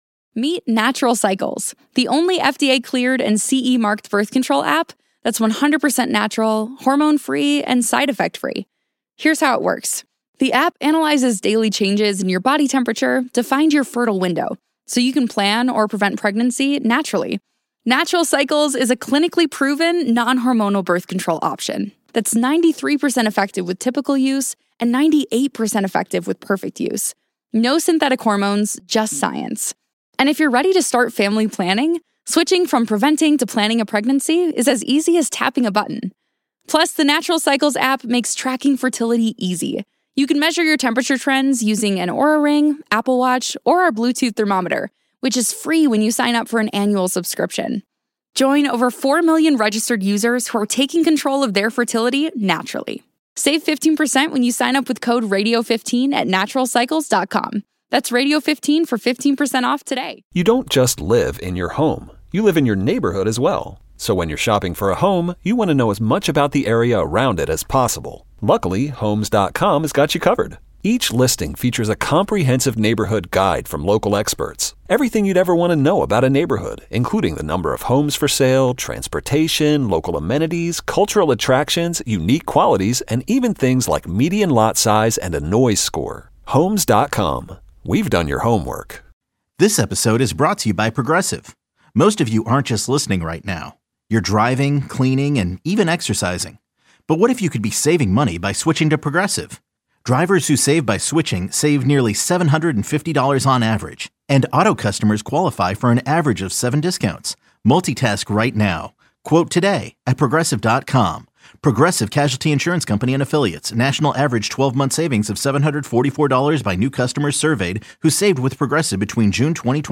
Deputy Supervisor of Amherst Major Shawn Lavin joins the show to discuss his run for Amherst Town Supervisor.